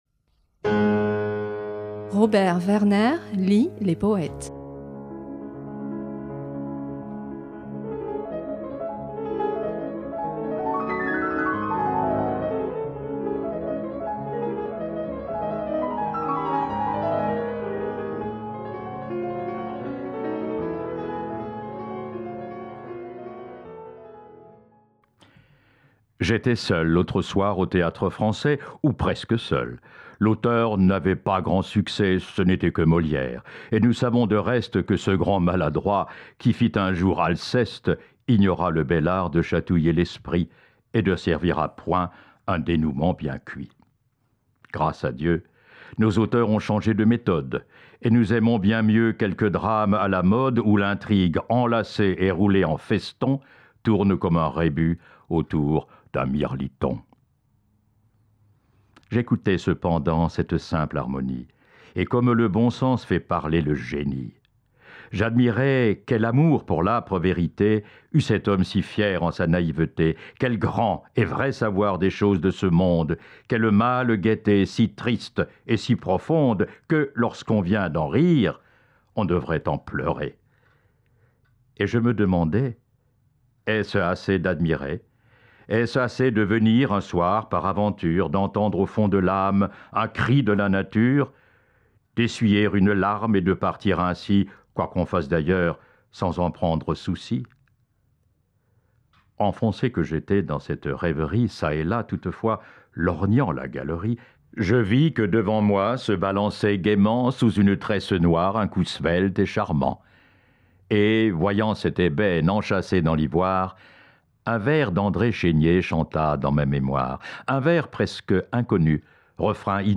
lit cette semaine Une soirée perdue, poème d’Alfred de Musset (1810-1857), extrait du recueil Poésies nouvelles, publié en 1850.